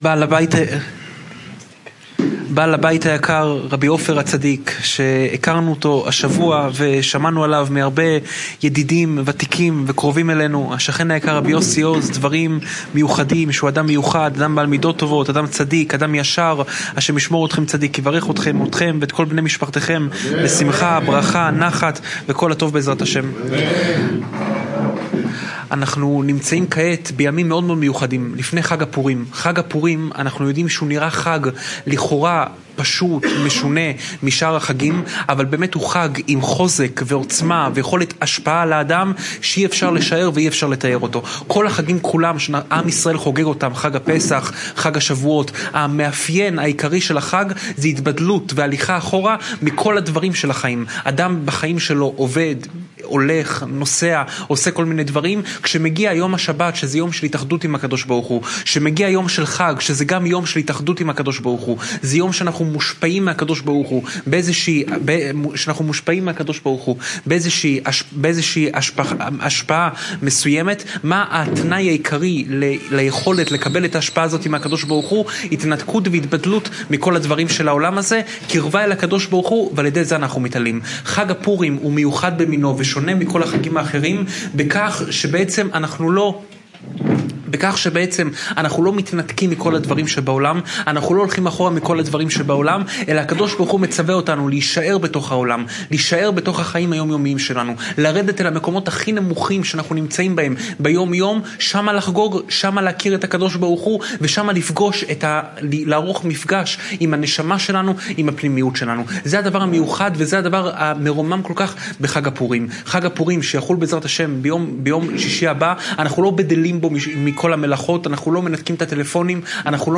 שעורי תורה מפי הרב יאשיהו יוסף פינטו